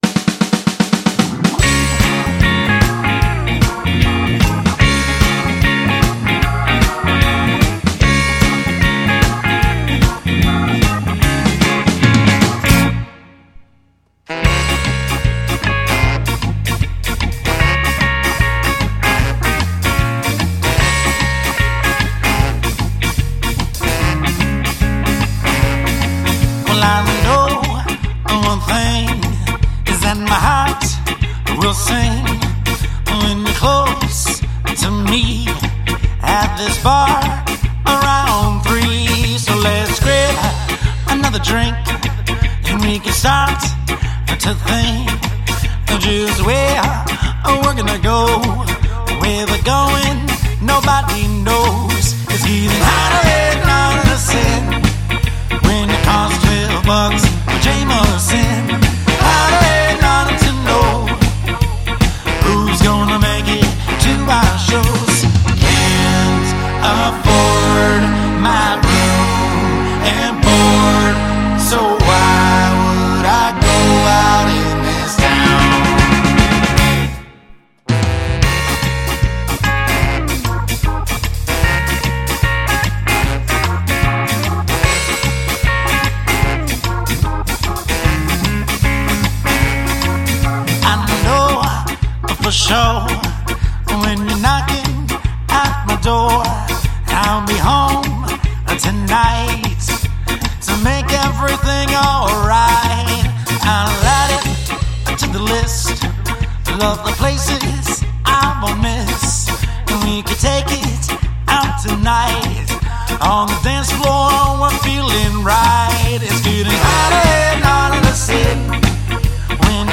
Genre: Ska.